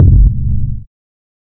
Kicks
KICKCRUNCH.wav